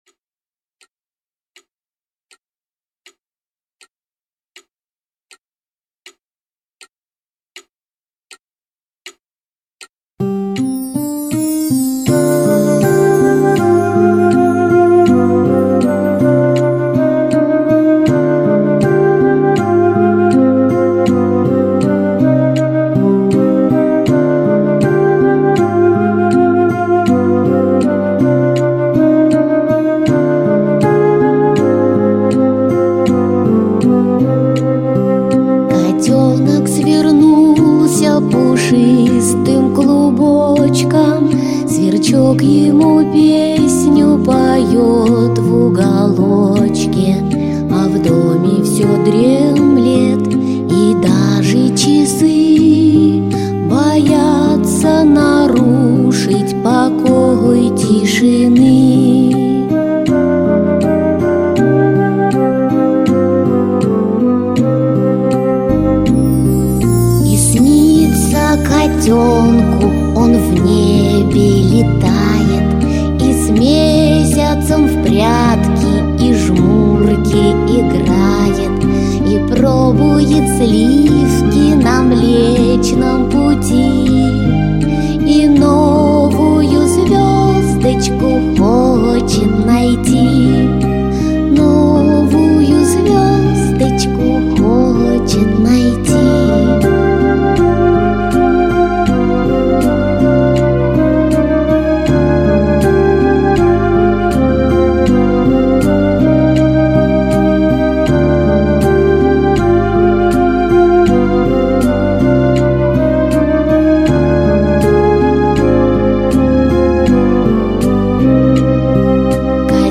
• Категория: Детские песни
Колыбельные песни